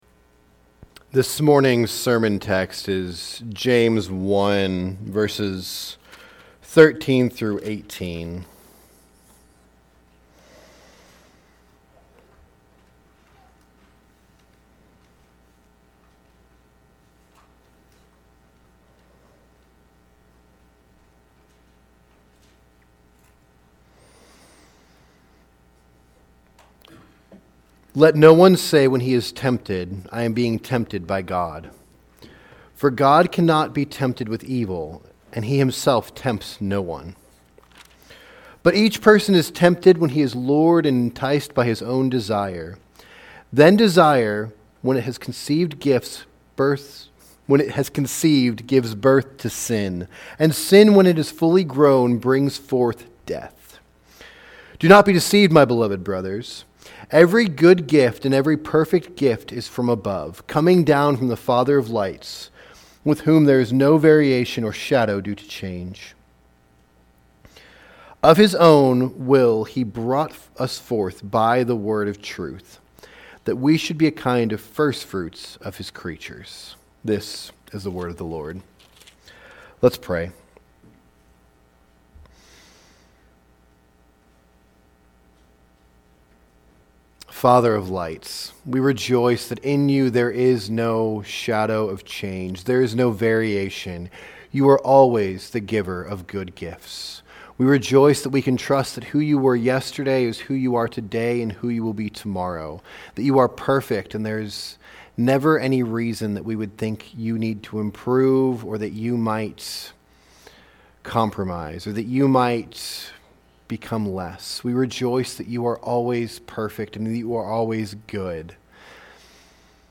Sermons | Maranatha Baptist Church